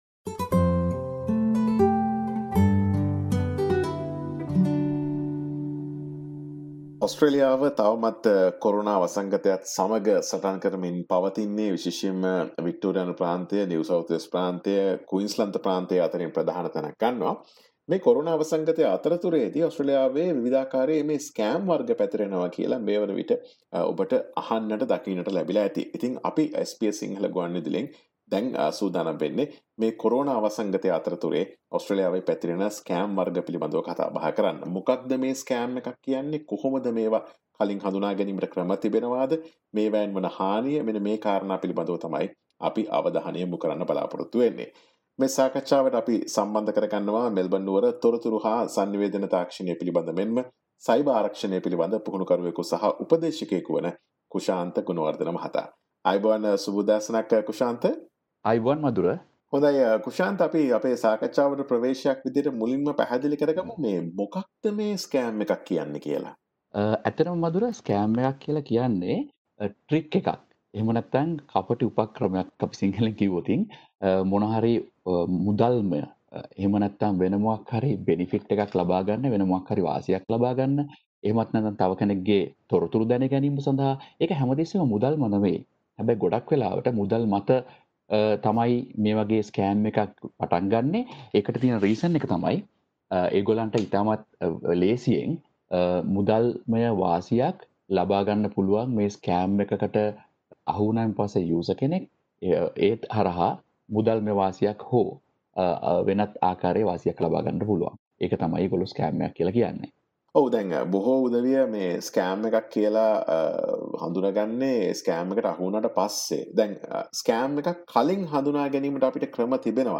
SBS සිංහල ගුවන් විදුලිය සිදුකළ සාකච්ඡාව.